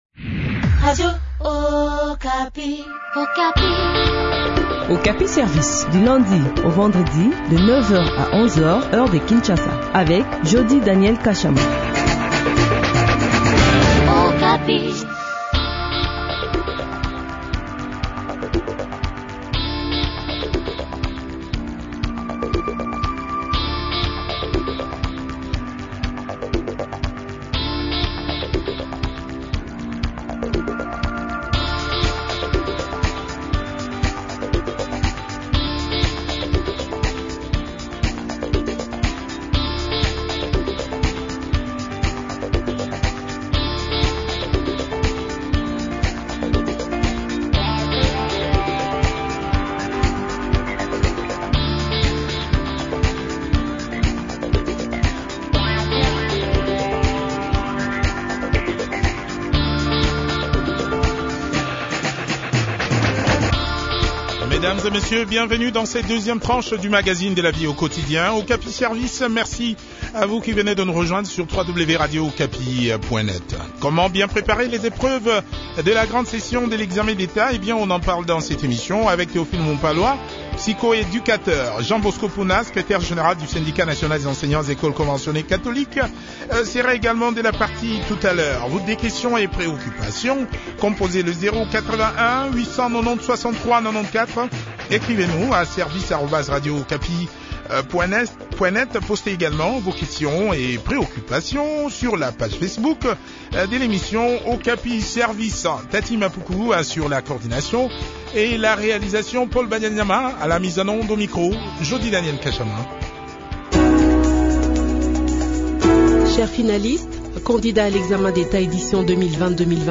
a aussi participé à cette interview.